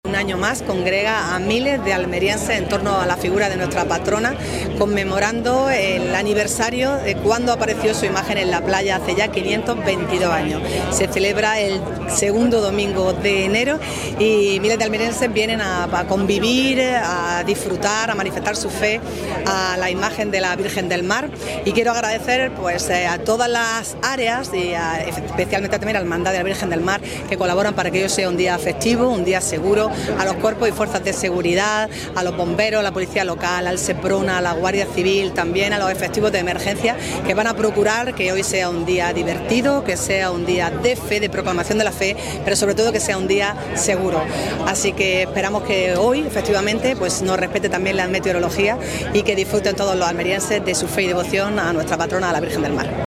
CORTE-ALCALDESA-1.mp3